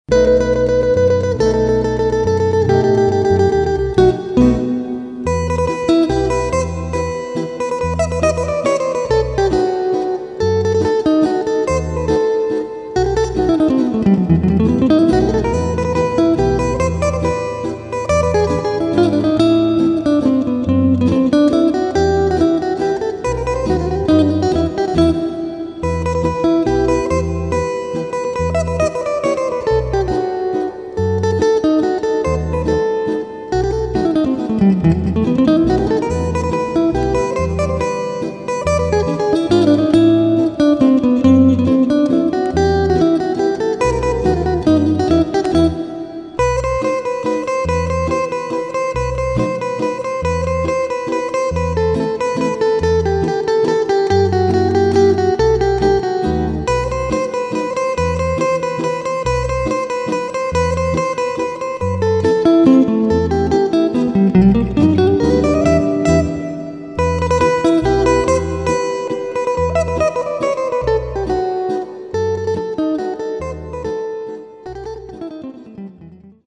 Mazurka per chitarra.